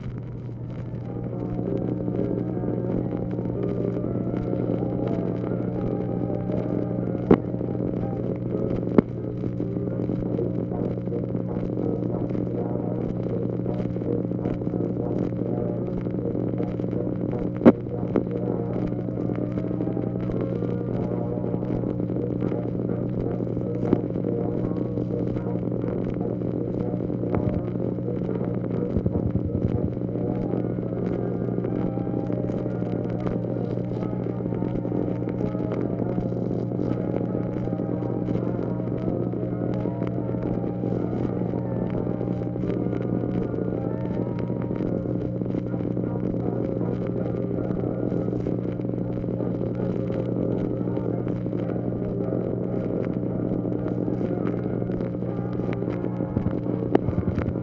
OFFICE NOISE, March 31, 1965
Secret White House Tapes | Lyndon B. Johnson Presidency